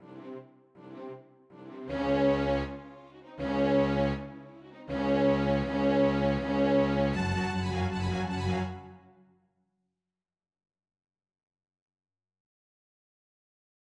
오프닝에서 크고 놀라운 C는 마침내 "설명"을 얻는다.
피날레 370-381마디
몇 마디 후, 이 조가 반음에 의해 "망치로 두들겨져" F장조의 으뜸조에 즉시 도달하는 놀라운 전조가 나타난다.